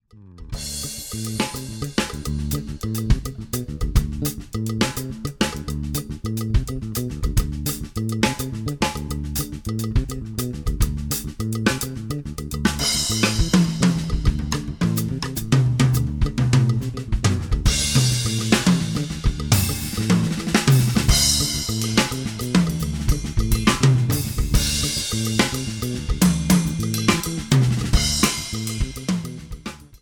A play-along track in the style of Afro-Cuban.
It is in the style of Afro-Cuban.